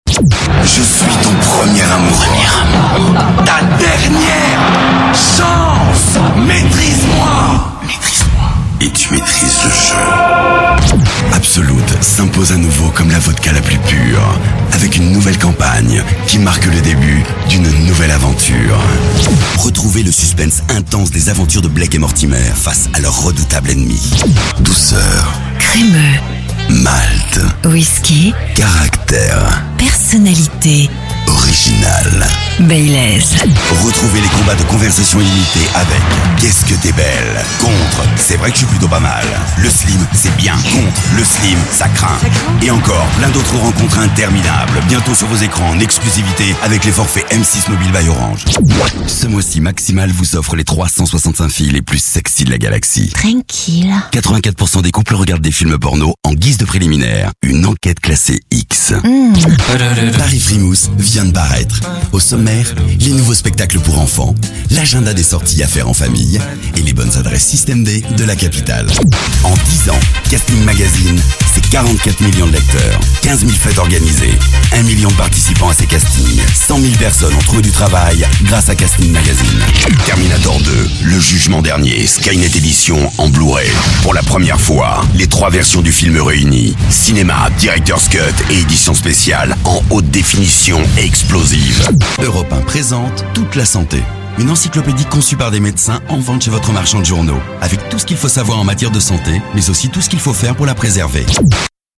Comédien Voix Off,voix grave;voix souriante ,voix tonique,voix sensuel,
Sprechprobe: Werbung (Muttersprache):